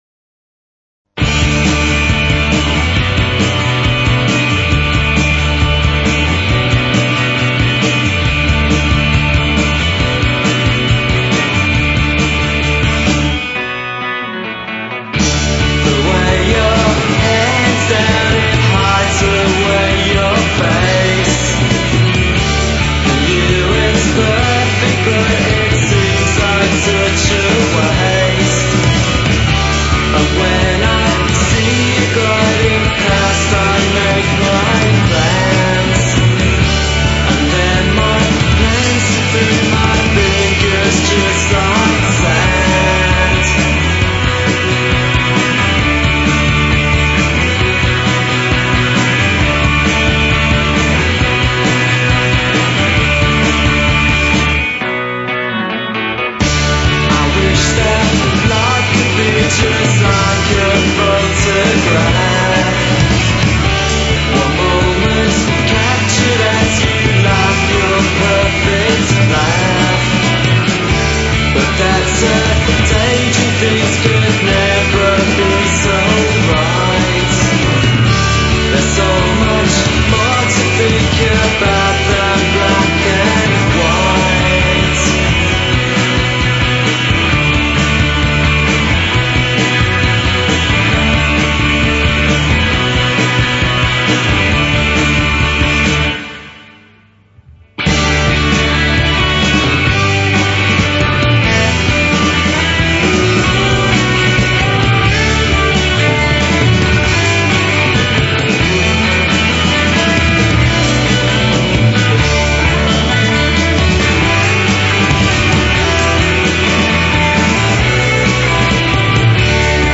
(Live at the BBC)